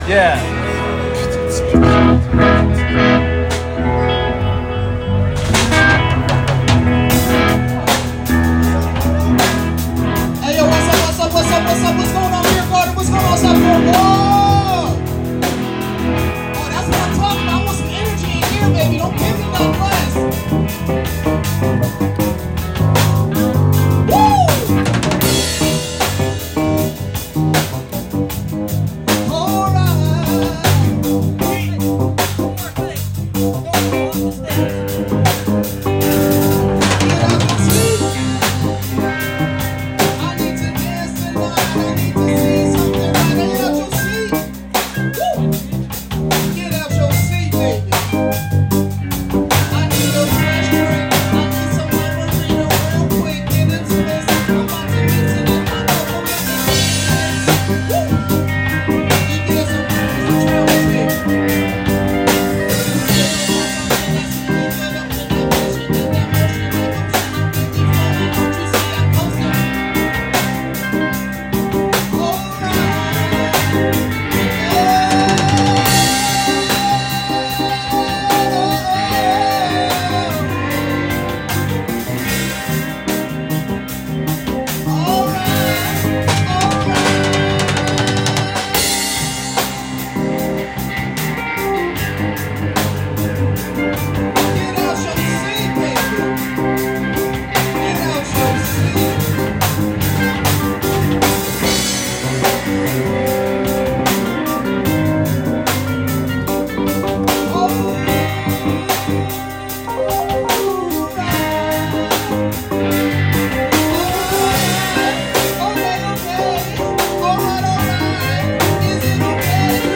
vocals
guitar
piano
bass
drums